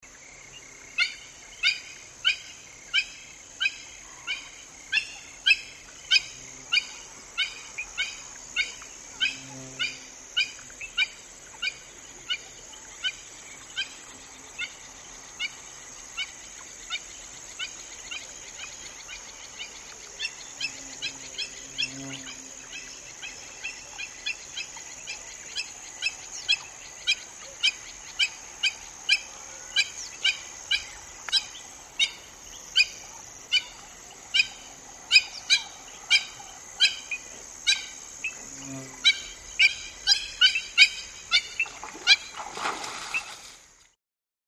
Hamerkop|African | Sneak On The Lot